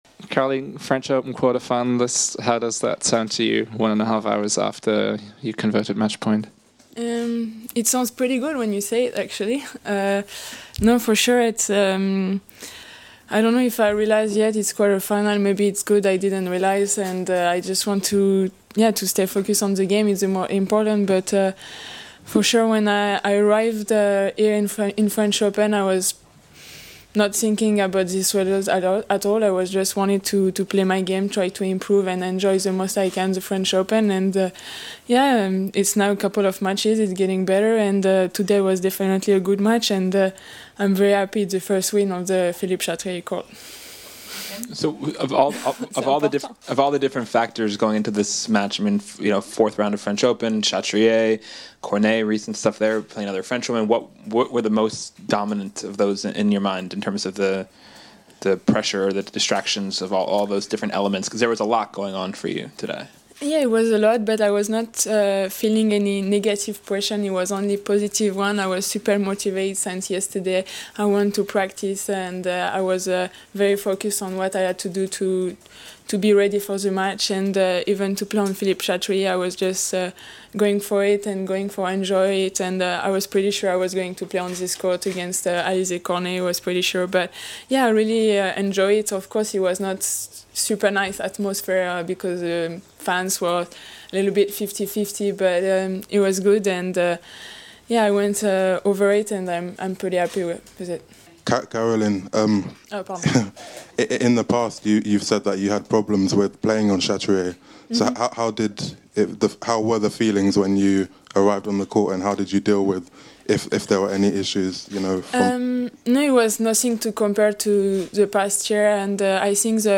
Here's Caroline after her 6-2, 6-4 win vs Alize Cornet in the 4th round at Roland-Garros